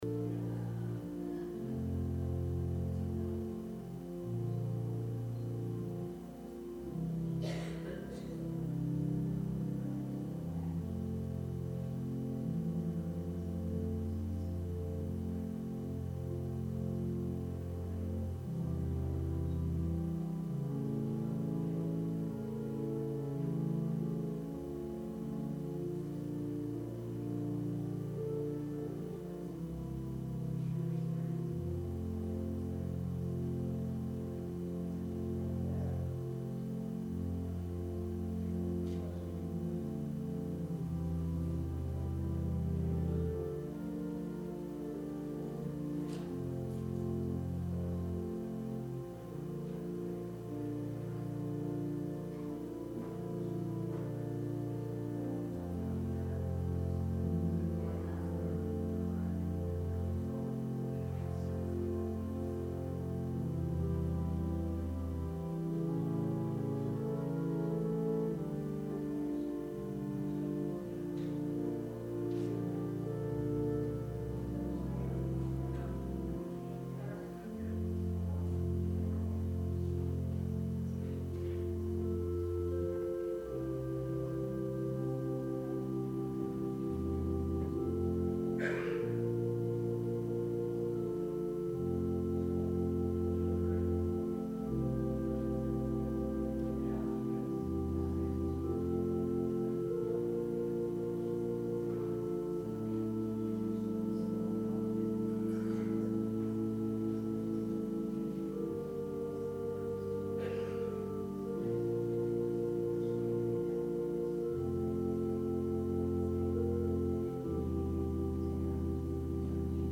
Sermon – January 19, 2020